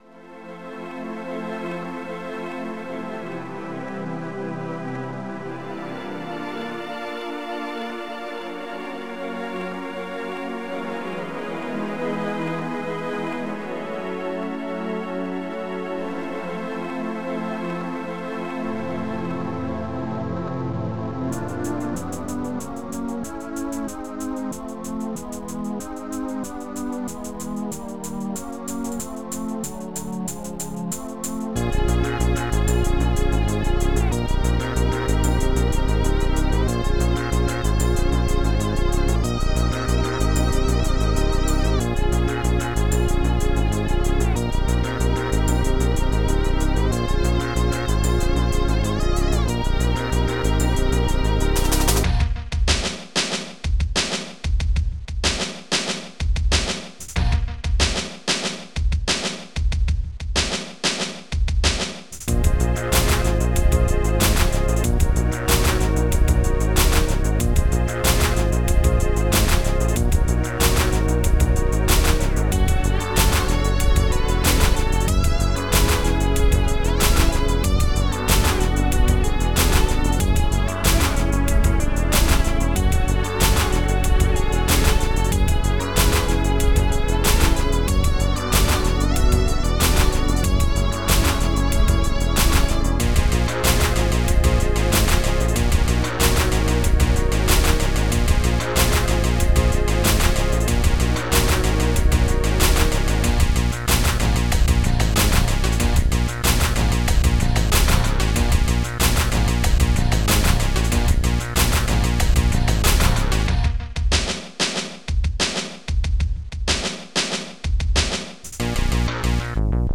Protracker and family
synth
strings2